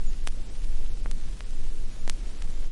乙烯基 " FE噪音
描述：黑胶唱片噪音。
Tag: 复古 葡萄酒 LP 乙烯基 转盘 记录 裂纹 表面噪声 专辑